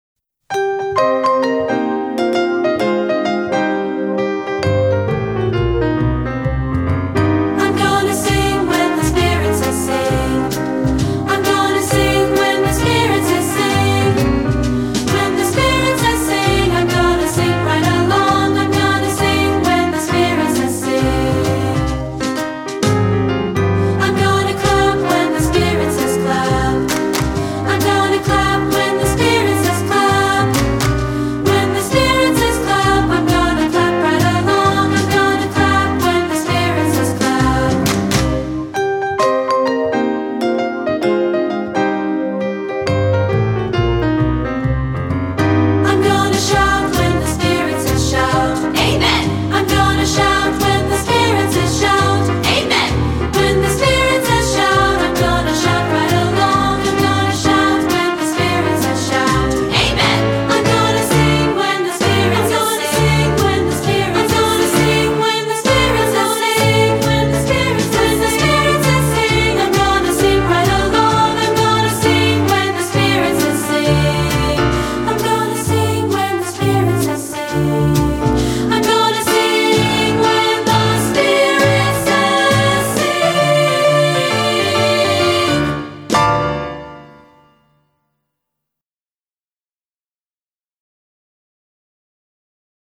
Composer: Traditional Spiritual
Voicing: Unison|2-Part